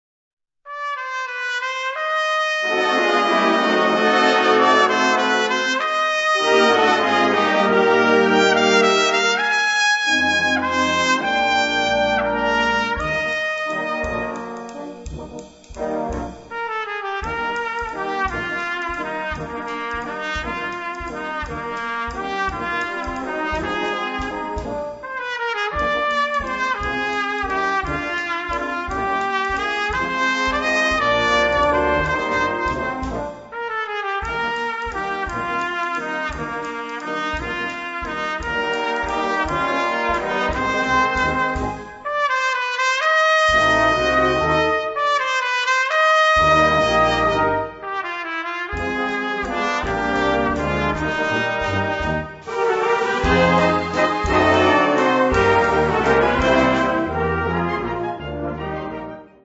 Gattung: Solo für Trompete und Blasorchester
Besetzung: Blasorchester